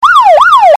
2 Police Sirens and Dukes of Hazzard Horn
Some quality horns I thought I would share
Just hold down the horn button and they repeat of course.